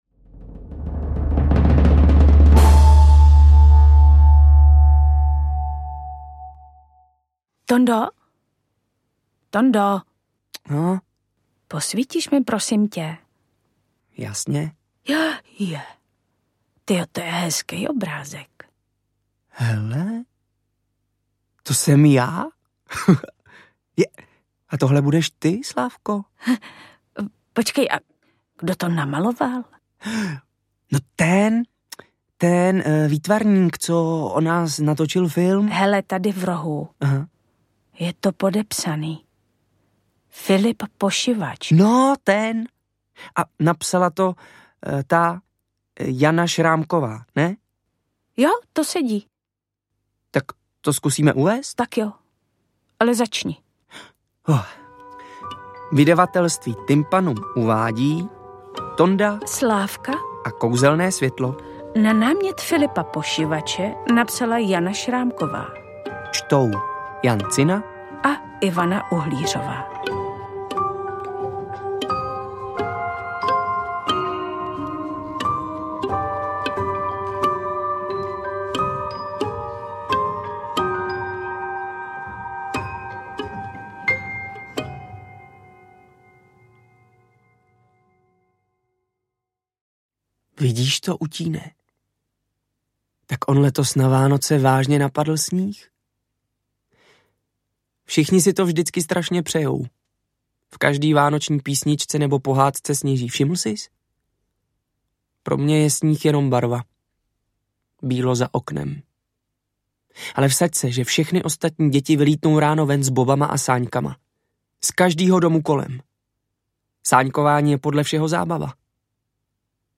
Interpreti:  Jan Cina, Ivana Uhlířová
AudioKniha ke stažení, 13 x mp3, délka 3 hod. 55 min., velikost 215,1 MB, česky